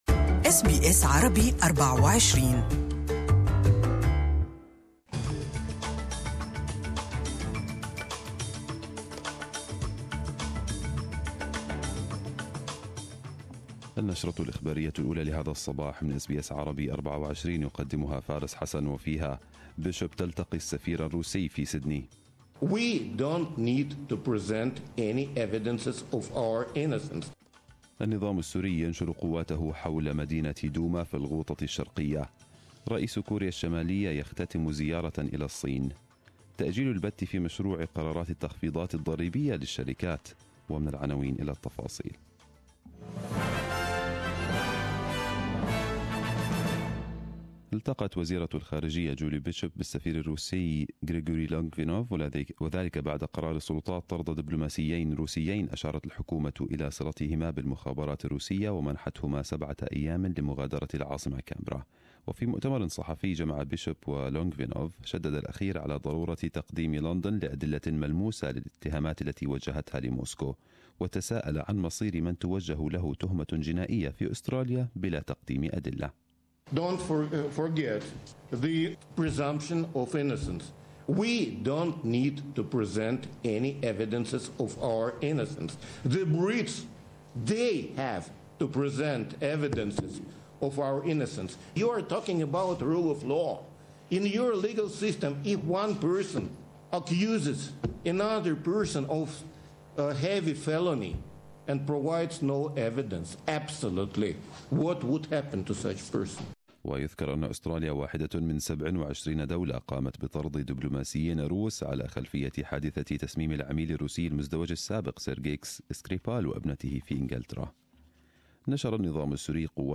Arabic News Bulletin 29/03/2018